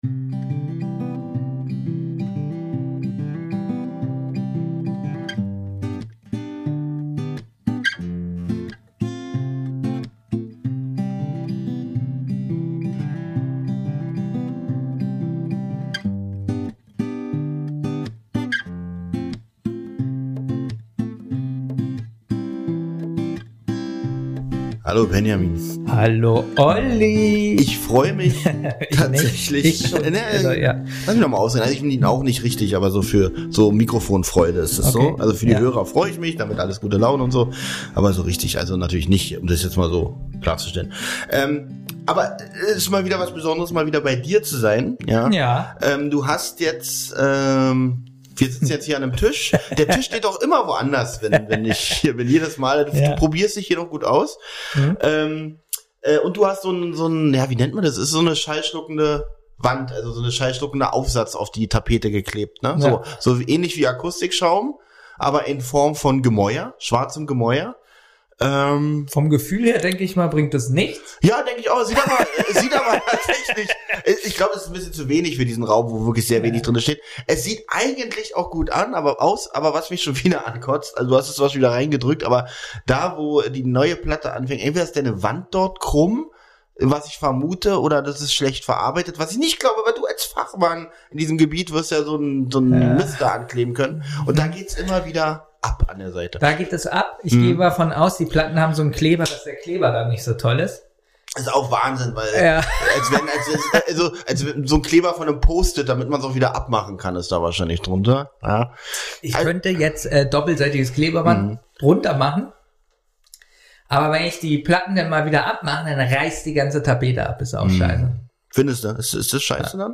Entschuldigen müssen wir uns erneuert wegen des Tons. Irgend etwas stimmt mit den Mikrofonen oder den Rodecastern nicht. wir arbeiten seit einigen Wochen daran, finden aber bisher nicht die Lösung.